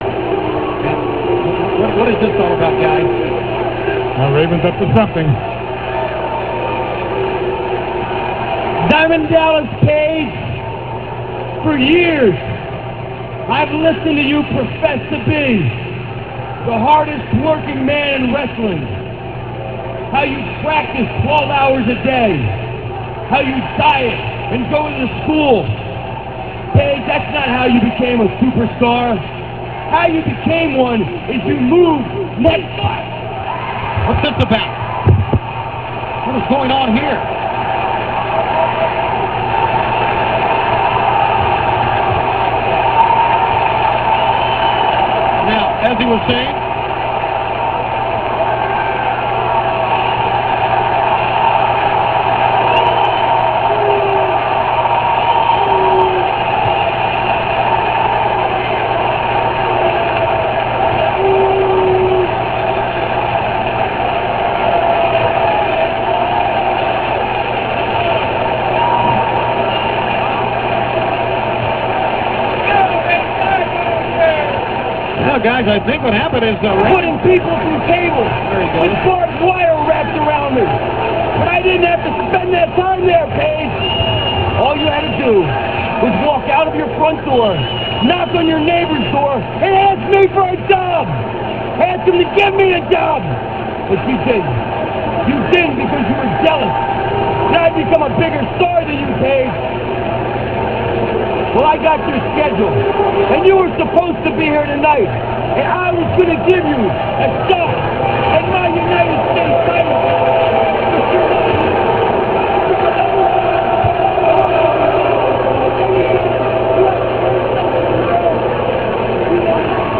- This speech comes from Thunder - [4.4.98]. Raven gets jumped by a fan in this promo & talks about how the only real reason Page is a champion is because he lives next door to Bischoff and he'll get his revenge on him.